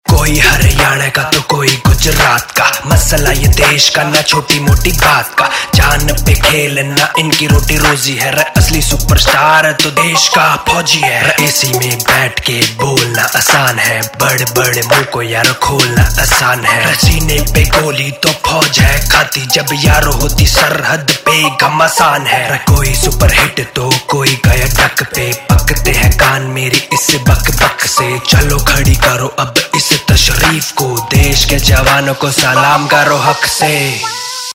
Indian POP Ringtones